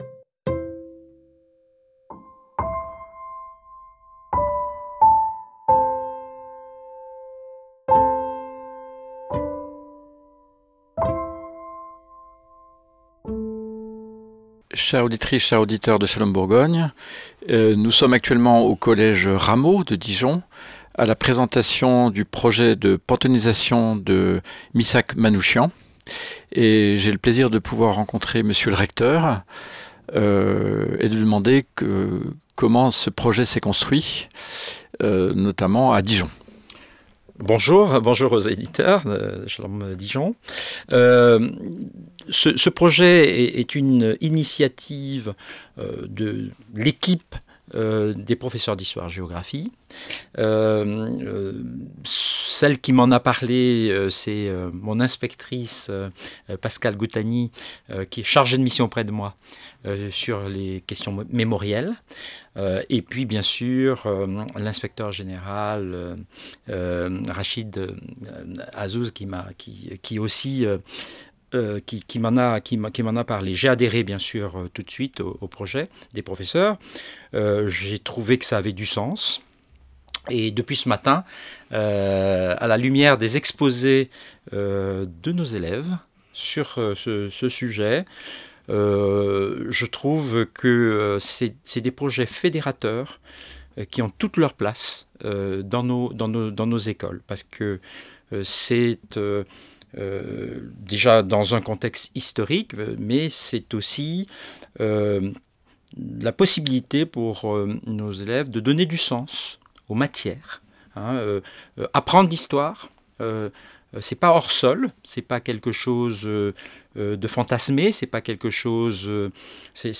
Lors de cette journée d'étude au sein collège Jean-Philippe Rameau consacrée à la Panthéonisation de Missak et Mélinée Manouchian, Monsieur Recteur de Côte d'Or Pierre N'Gahane était présent et il a répondu aux questions de